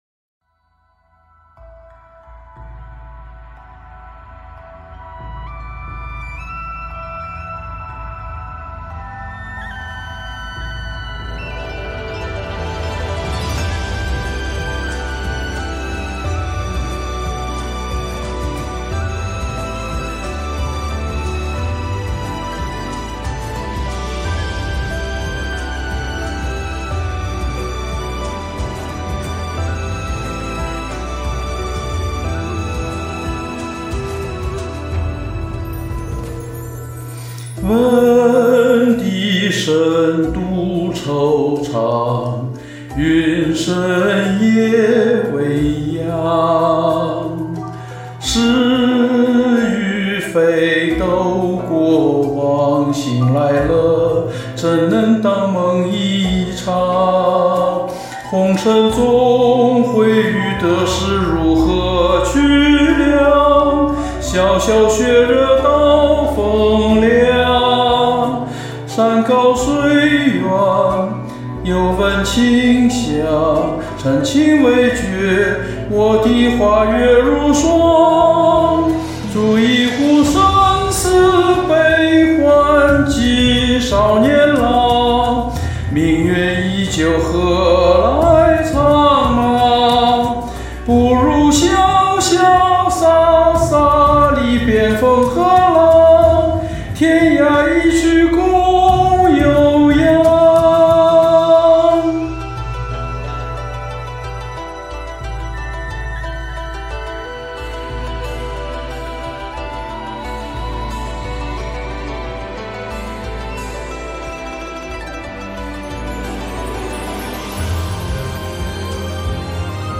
如果歌声音量小一些或伴奏强一些会更好
声音干净醇厚，演唱自然，流畅
声音很洪亮。